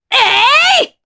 Naila-Vox_Attack4_jp.wav